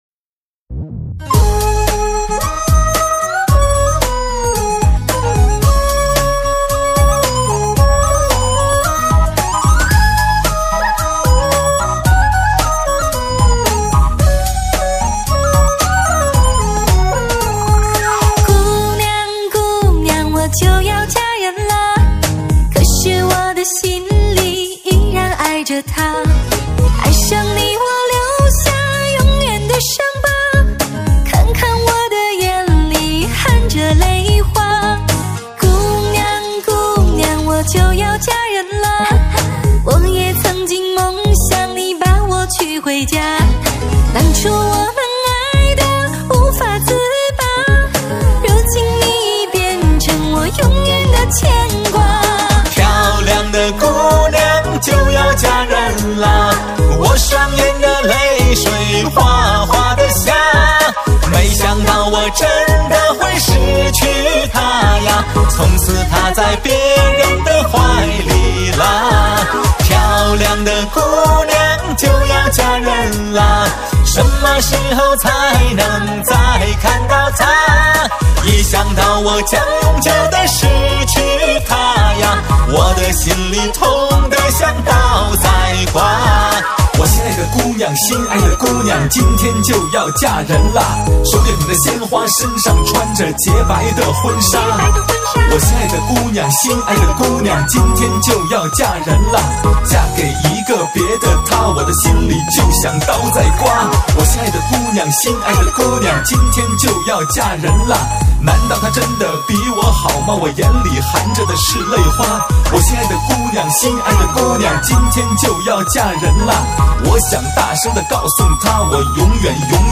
华语流行